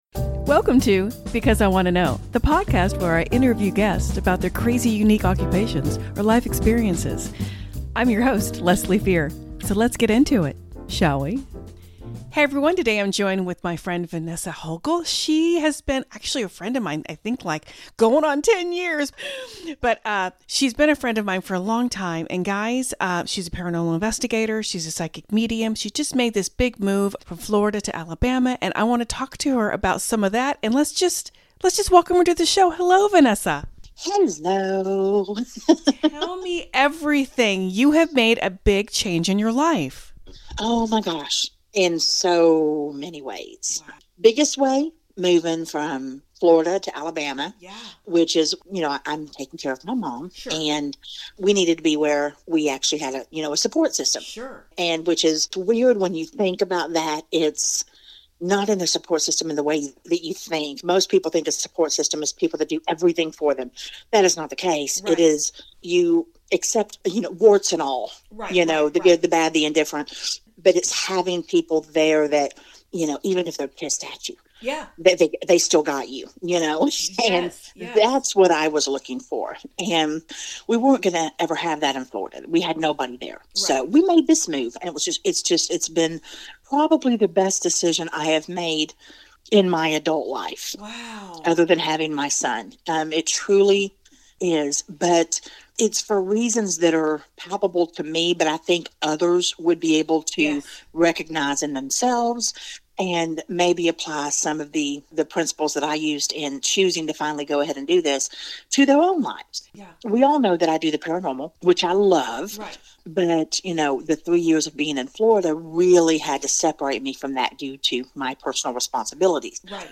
Each week, I interview guests who have crazy-unique occupations or life experiences. It could be anyone from a Mortician, a Near Death Experiencer, to a True Crime Event or a Religious Cult Escapee.